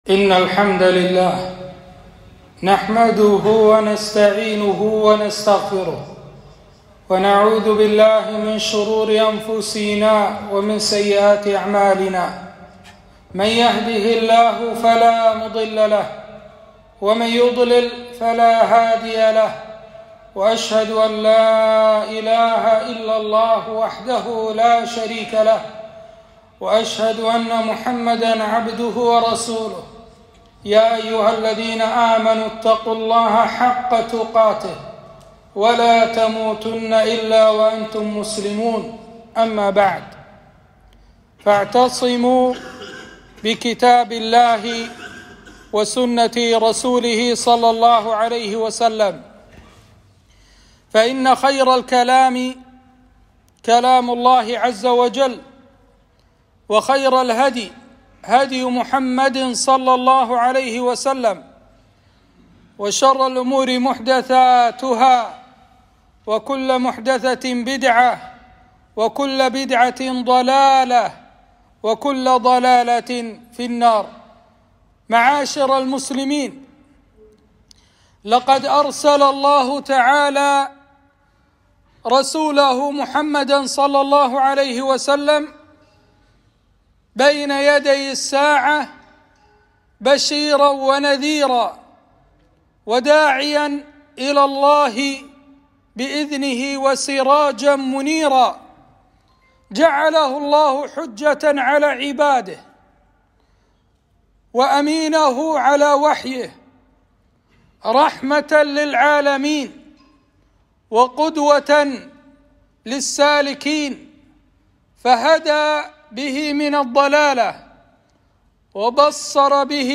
خطبة خطورة الابتداع وشؤم البدع